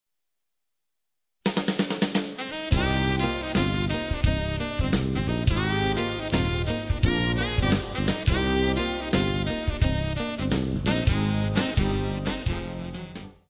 Theme Song (MP3)